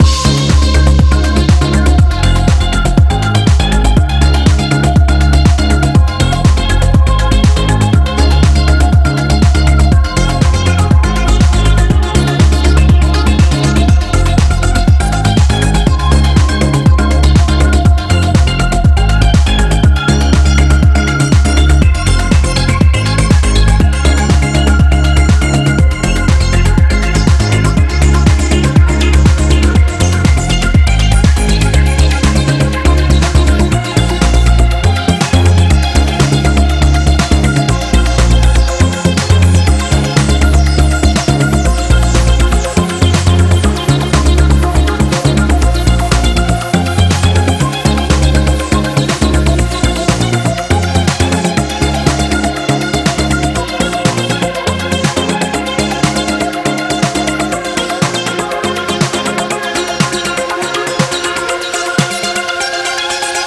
ジャンル(スタイル) HOUSE / BALEARIC / DISCO